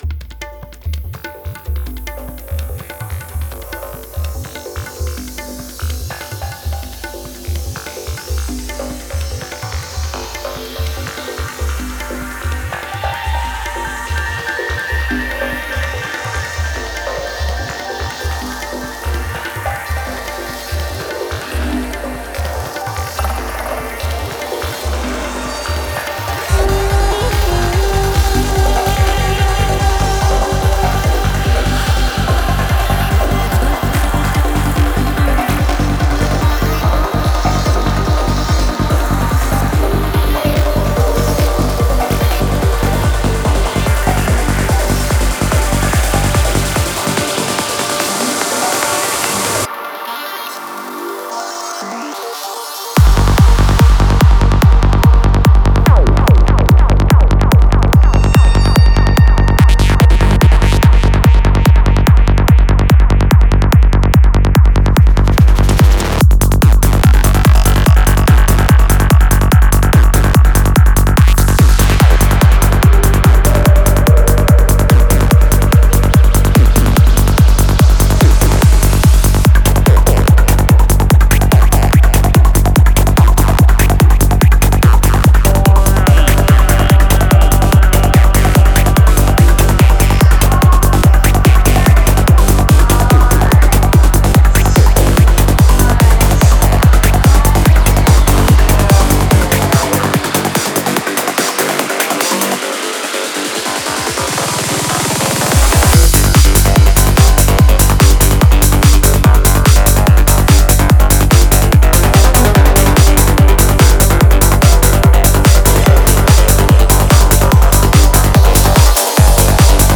Genre: Goa, Psychedelic Trance.